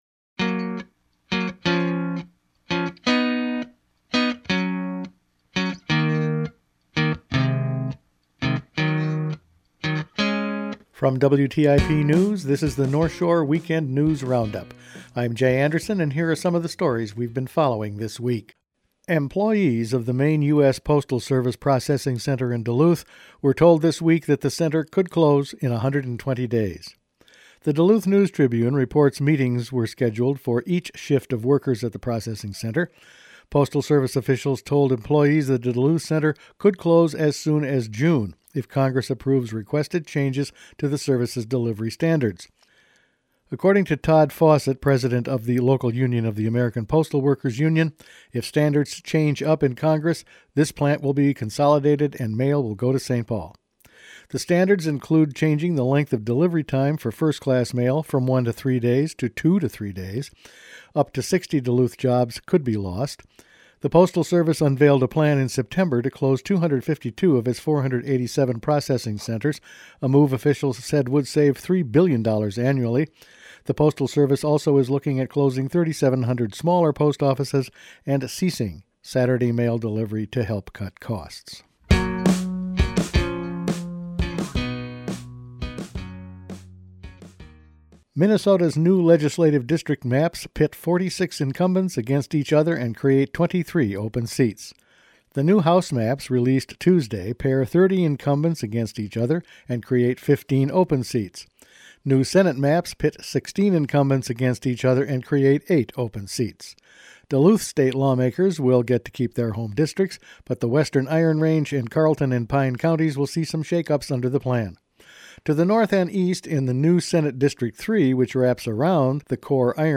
Weekend News Roundup for Feb. 25
Each weekend WTIP news produces a round up of the news stories they’ve been following this week. Duluth post office closure, impacts of the new redistricting plan, Duluth Air Base cuts and almost time to remove fish houses from area lakes …all in this week’s news.